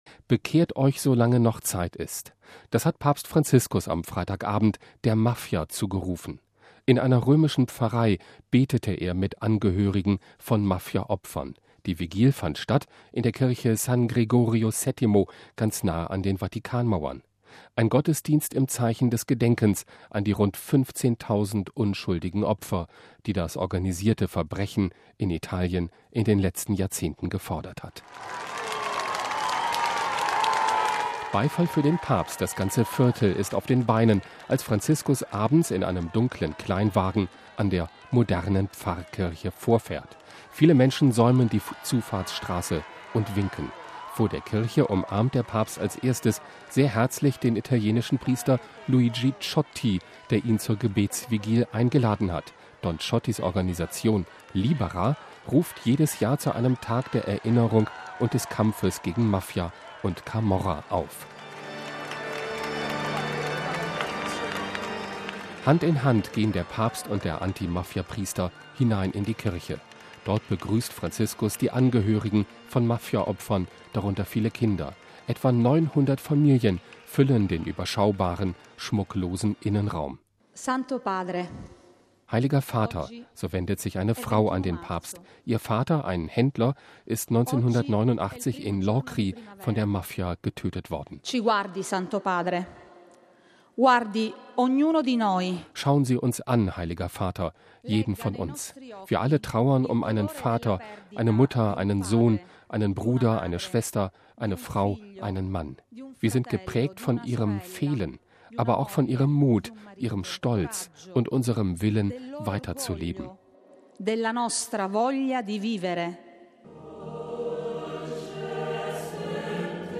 Das hat Papst Franziskus am Freitagabend der Mafia zugerufen. In einer römischen Pfarrei betete er mit Angehörigen von Mafia-Opfern; die Vigil fand in der Kirche San Gregorio VII. nahe der Vatikanmauern statt.
Beifall für den Papst: Das ganze Viertel ist auf den Beinen, als Franziskus abends in einem dunklen Kleinwagen an der modernen Pfarrkirche vorfährt.
Ein Chor singt den Sonnengesang des heiligen Franziskus, dann werden, einer nach dem anderen, die Namen der von der Mafia Getöteten verlesen.